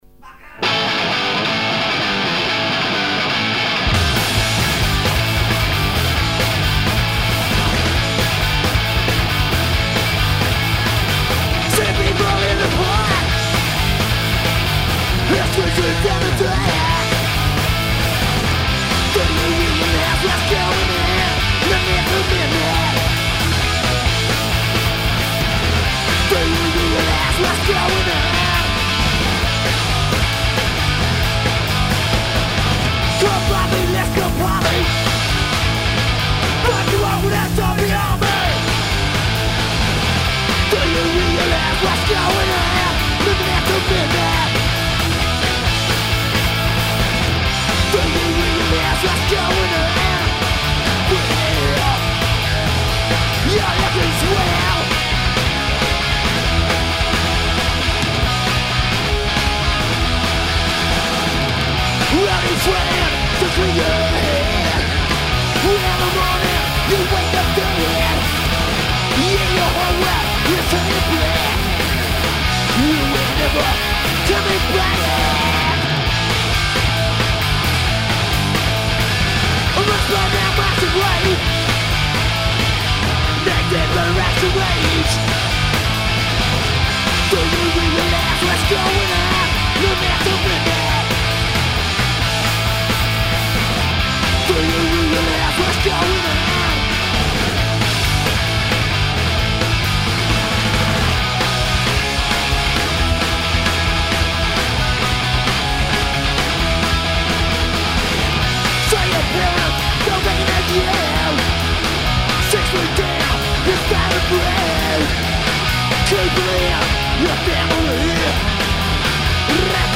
guitar
drums + backing vox